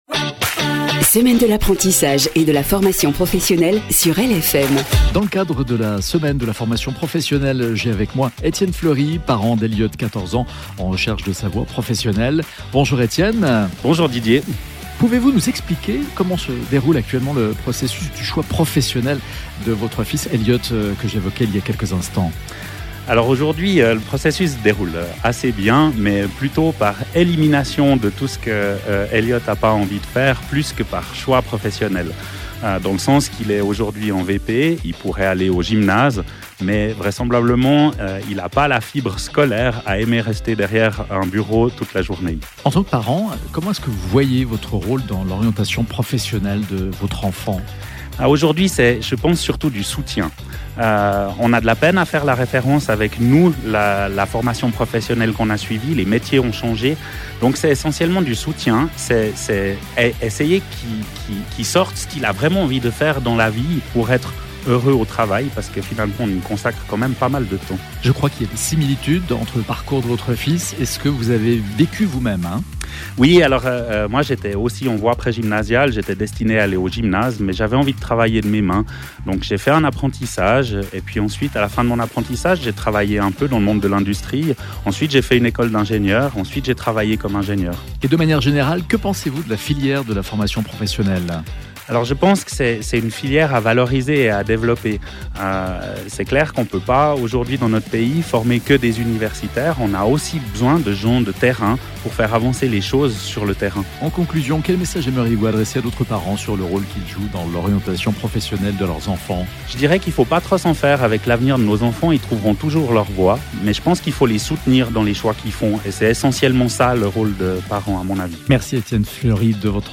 Dans le canton de Vaud, des invités parleront de leur expérience ou de leur vision du choix d’un apprentissage, chaque jour à 16h18 et à 18h48 sur LFM.
Programme des interviews